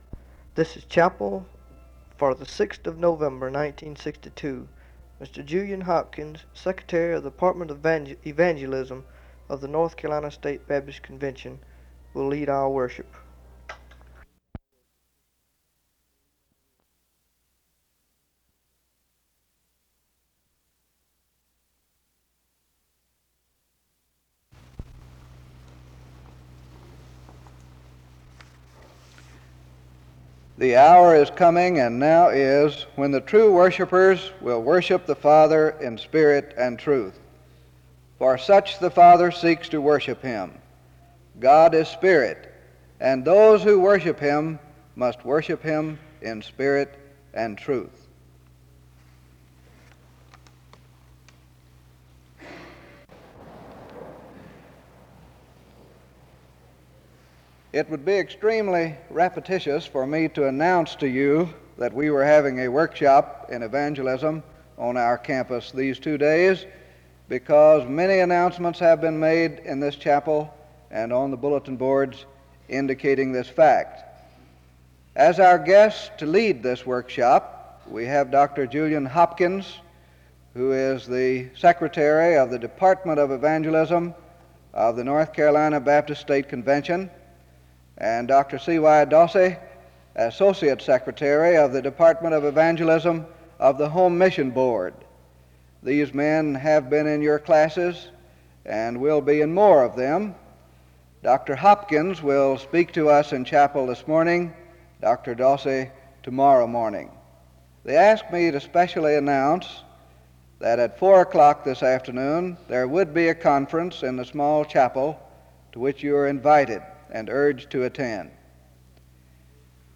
The service begins with scripture reading and announcements from 0:30-2:10. There is a prayer from 2:13-2:58.
SEBTS Chapel and Special Event Recordings SEBTS Chapel and Special Event Recordings